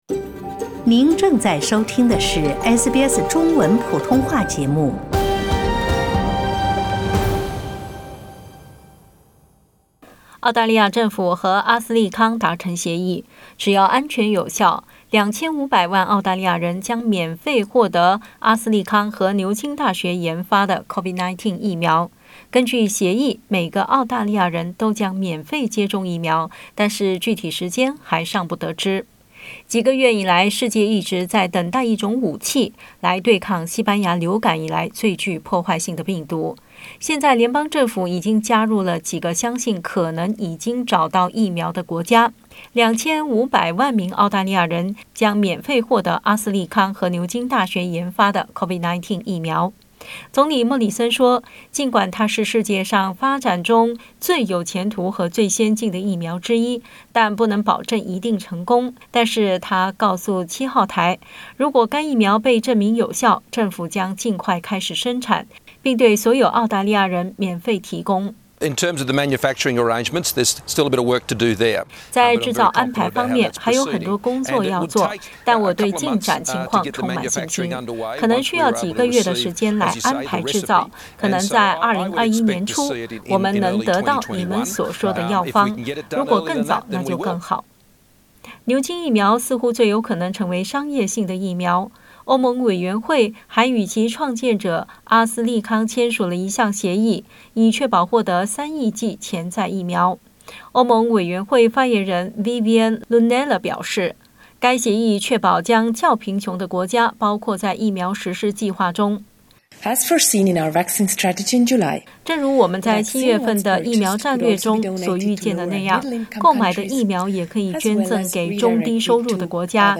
总理莫里森（Scott Morrison）承诺，政府将确保向澳大利亚的太平洋邻国和东南亚合作伙伴提供任何成功的疫苗。 点击图片收听详细报道。